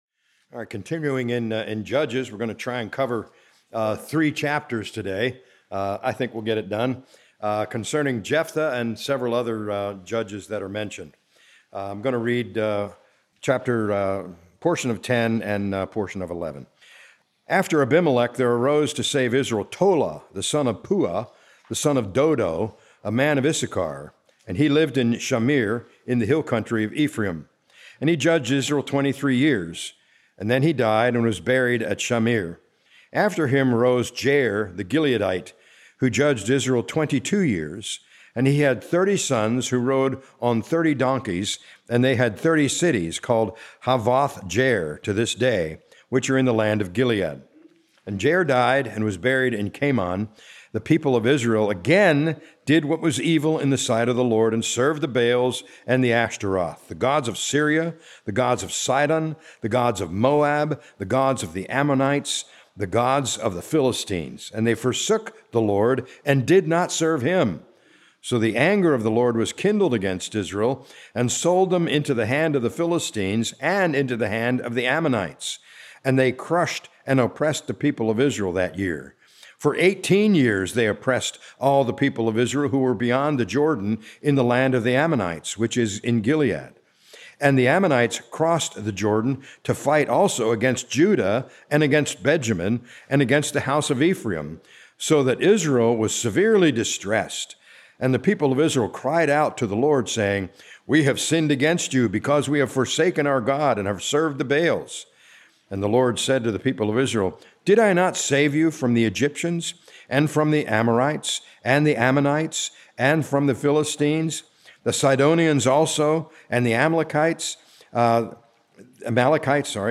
A message from the series "Judges 2025."